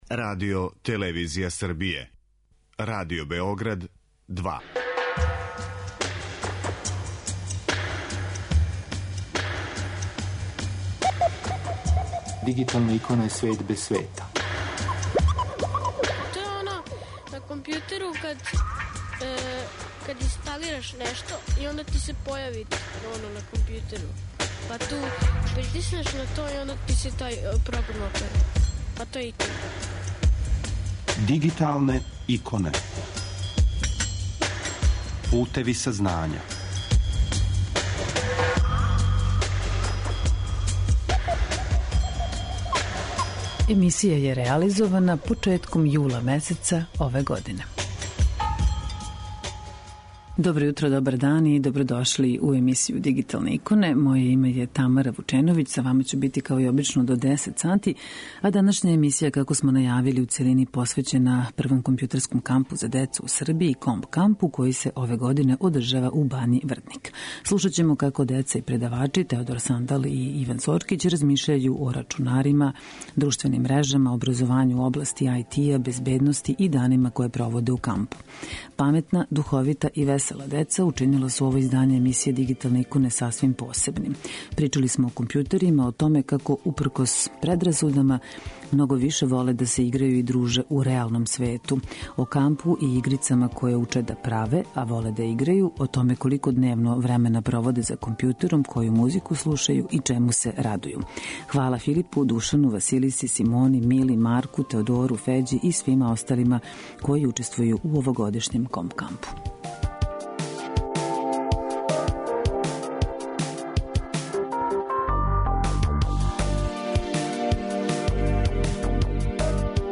Можете чути како деца и предавачи размишљају о рачунарима, друштвеним мрежама, образовању у области ИТ, безбедности, игрању са другарима и данима које проводе у кампу.